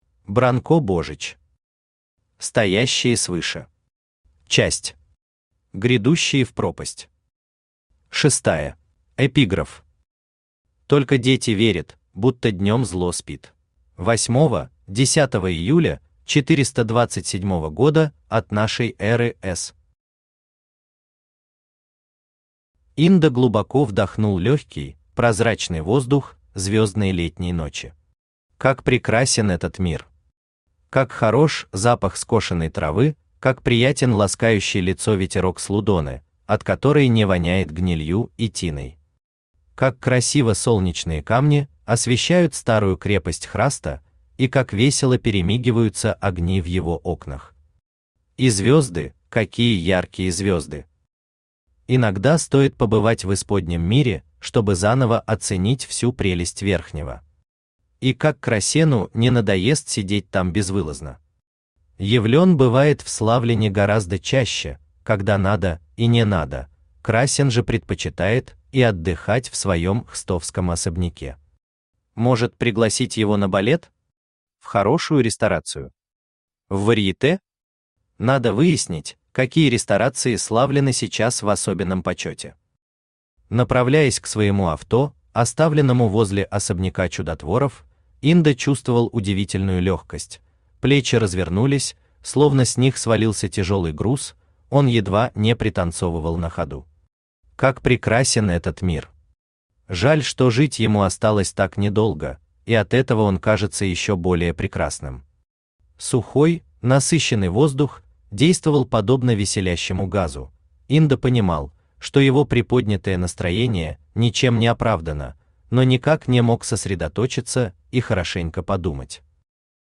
Аудиокнига Стоящие свыше. Часть VI. Грядущие в пропасть | Библиотека аудиокниг
Грядущие в пропасть Автор Бранко Божич Читает аудиокнигу Авточтец ЛитРес.